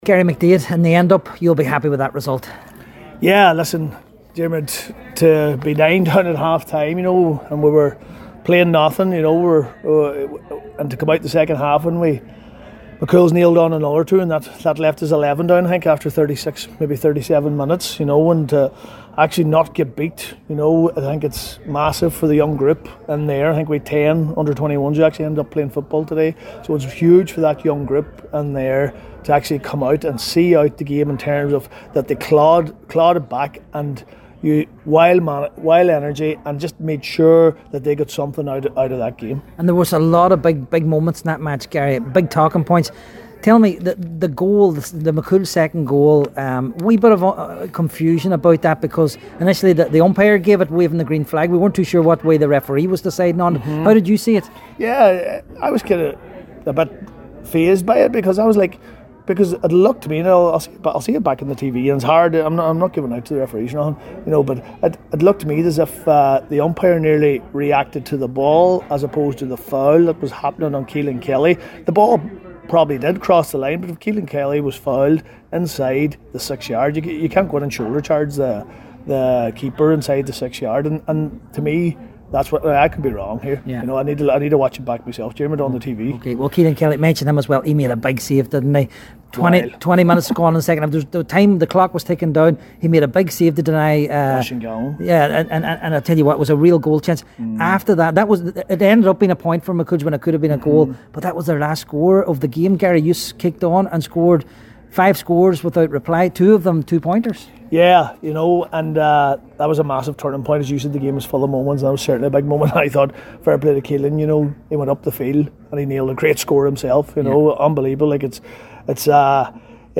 at full time this afternoon…